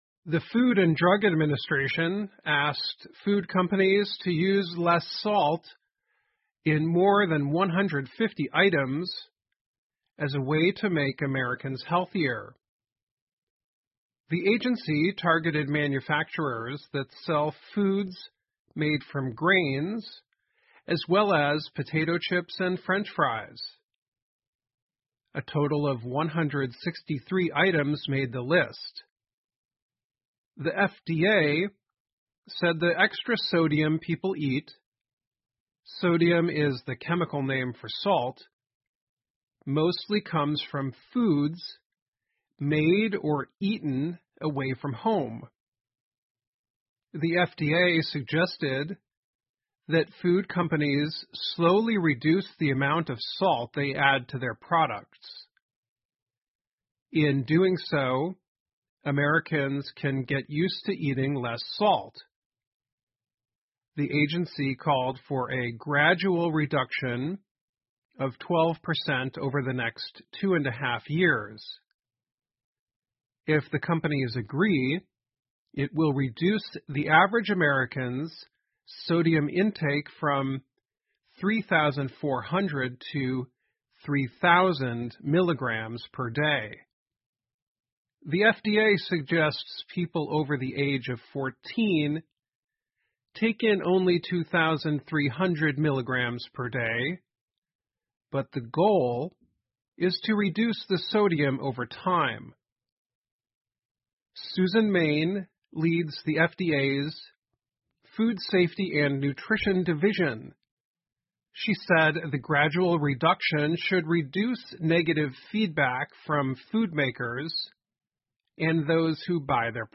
VOA慢速英语--FDA最新饮食建议:食品制造商应少放盐 听力文件下载—在线英语听力室